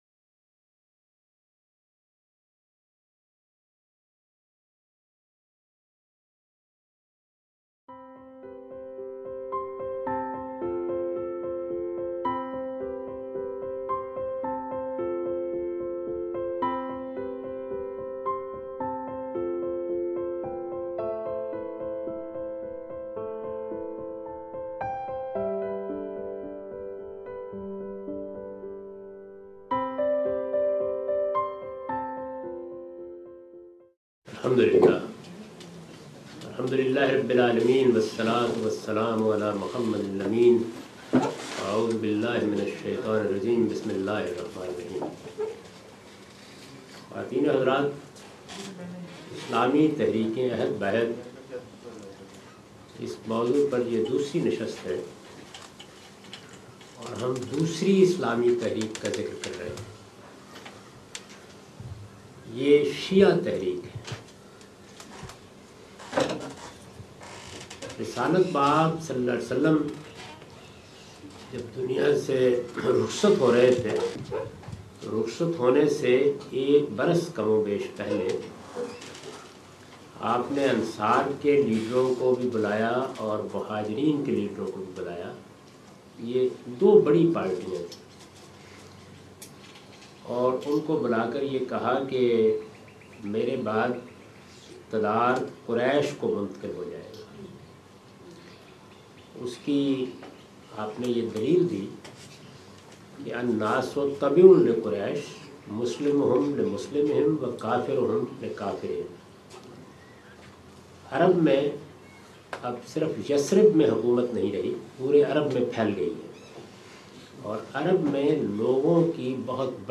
This series of lectures was recorded in Australia in January 2014.